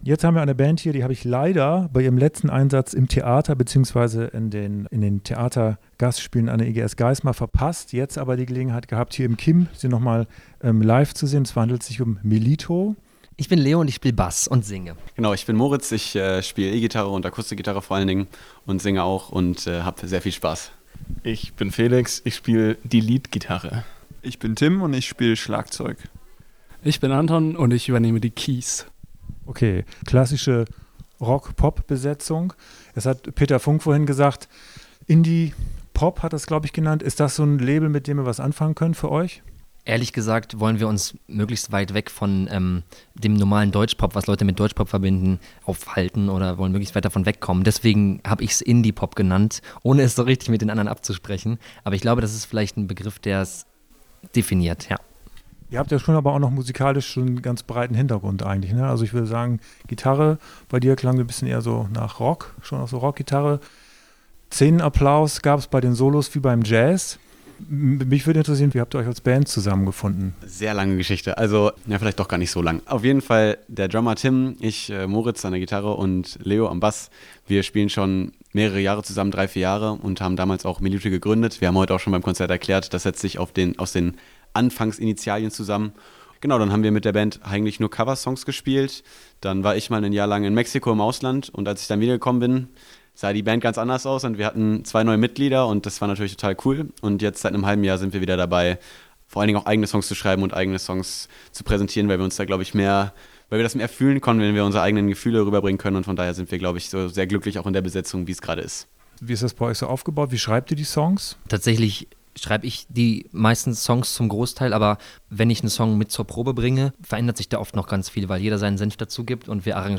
Indiepop mit Theaterappeal: Interview mit der Göttinger Band Melito